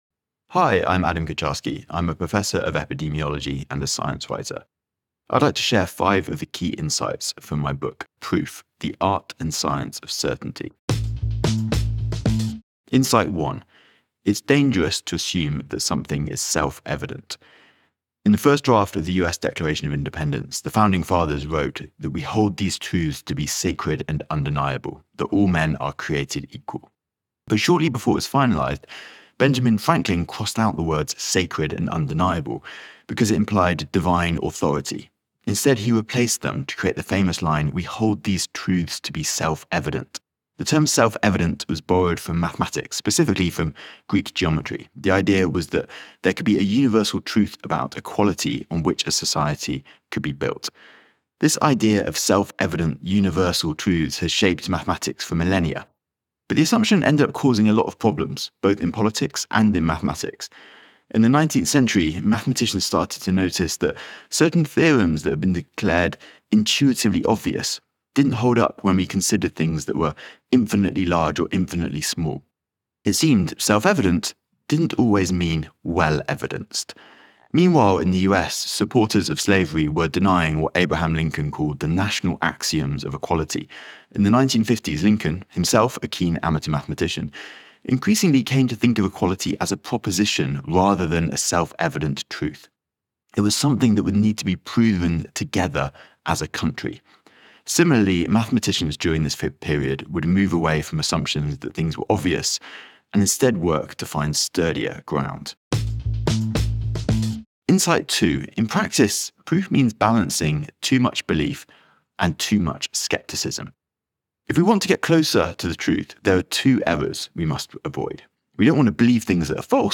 Below, Adam shares five key insights from his new book, Proof: The Art and Science of Certainty. Listen to the audio version—read by Adam himself—in the Next Big Idea App.